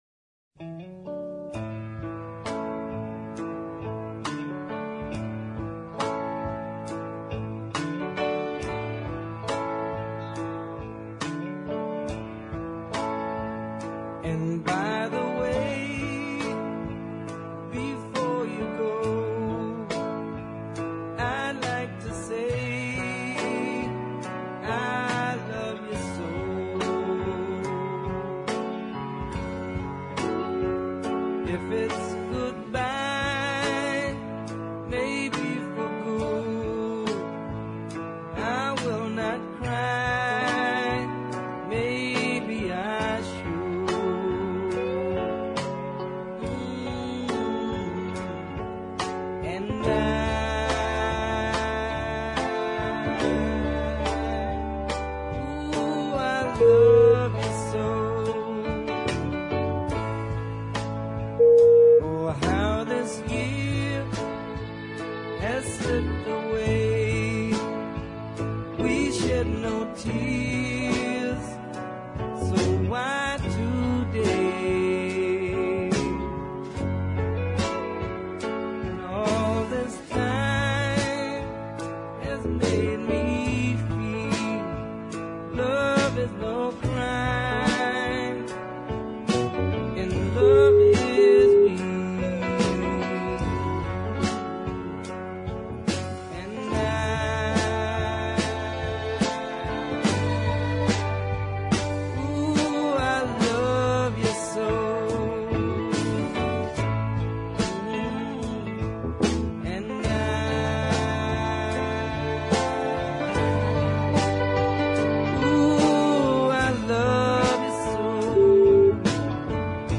And the strings add a little sadness.